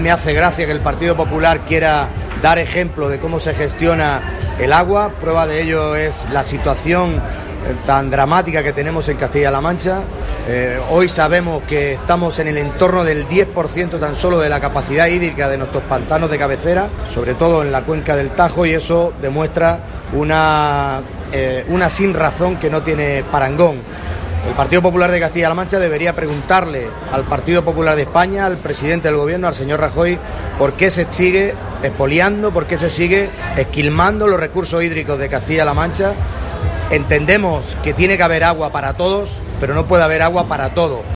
El parlamentario regional ha realizado estas declaraciones en un encuentro con los medios de comunicación que ha tenido lugar en el stand del PSOE, en la Feria de Albacete.
Cortes de audio de la rueda de prensa